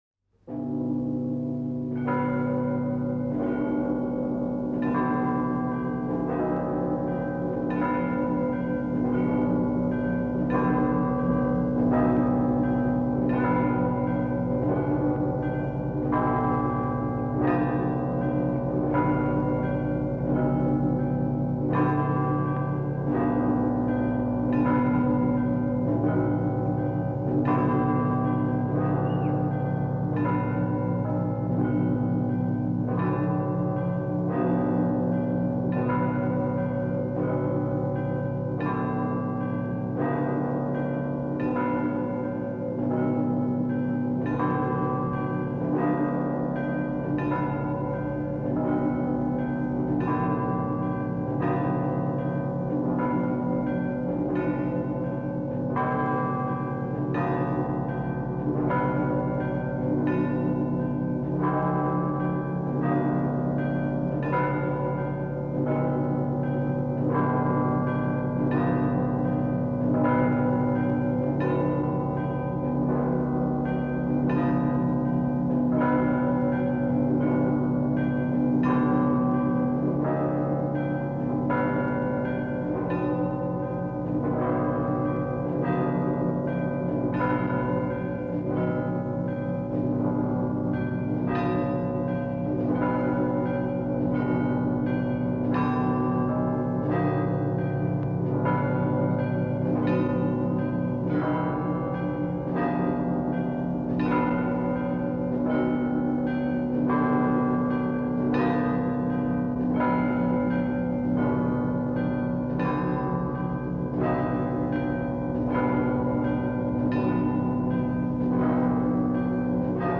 Index of /lib/fonoteka/etnic/rossia/kolokola/rostov-2
08_Ioakimovskij_Rostovskij_Perezvon.mp3